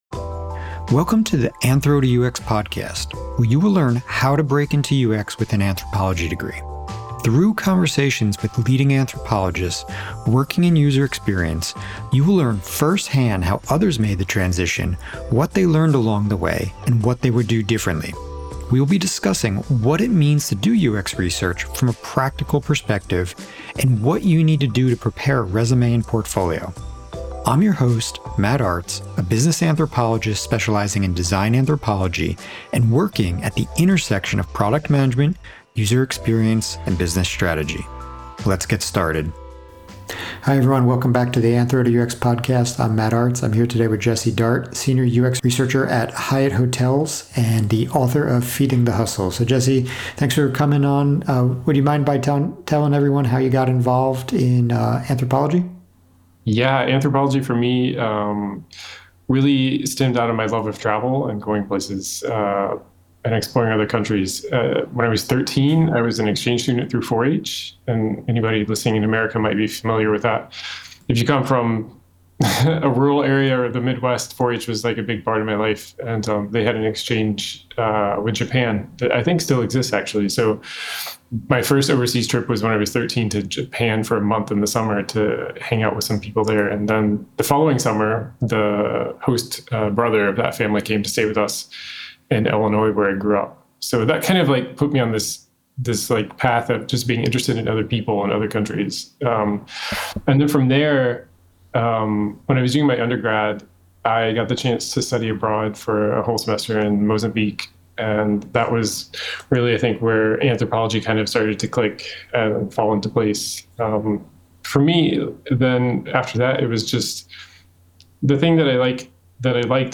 Interview 2